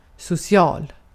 Ääntäminen
IPA : /ˈfrɛndli/